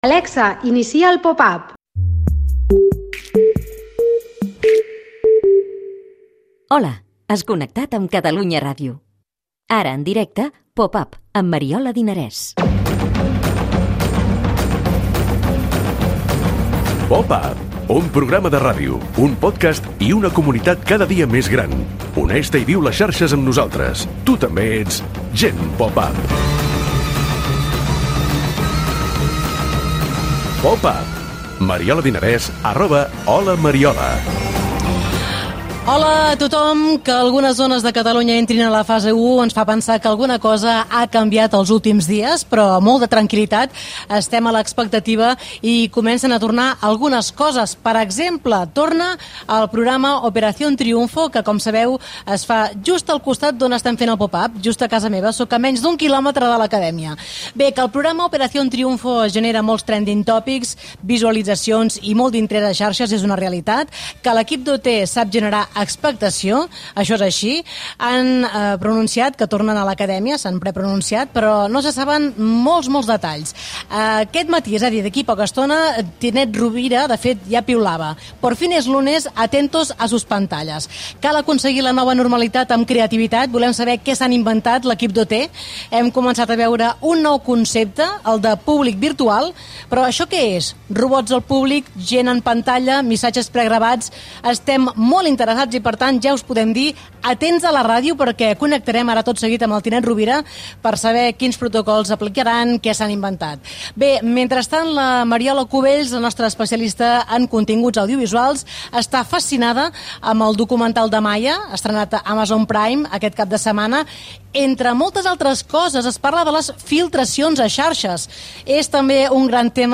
Retornen les gales del programa '"Operación triunfo" a TVE, amb restriccions, protocols sanitaris i públic virtual. Entrevista